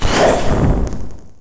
erupt.wav